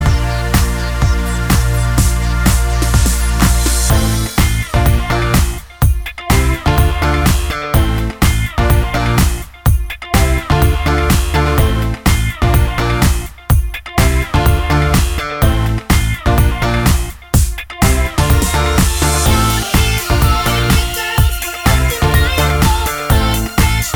For Solo Female Pop (2000s) 3:53 Buy £1.50